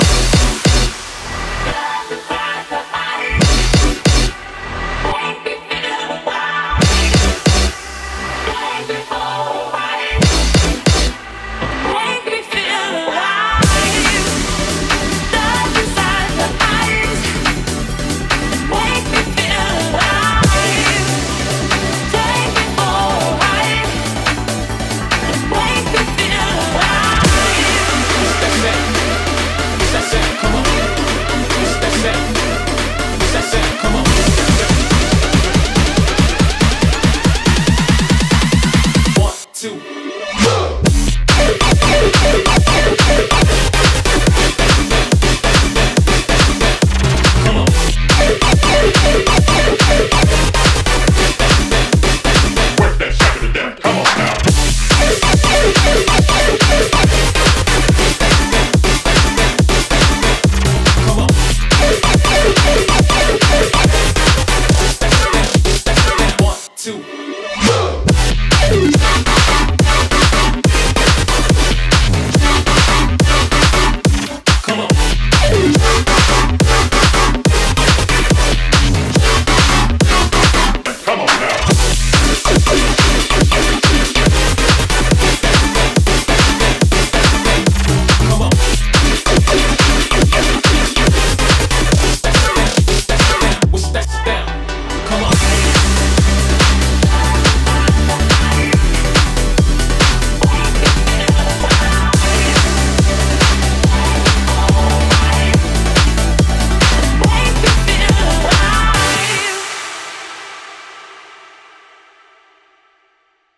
BPM141
Comments[ELECTRO]